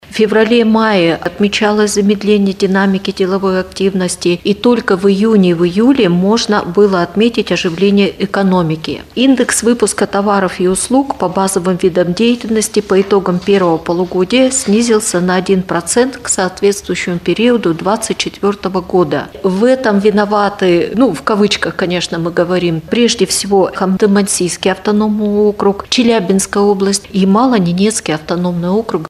на пресс-конференции «ТАСС-Урал».